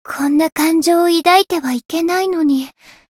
灵魂潮汐-薇姬娜-问候-不开心.ogg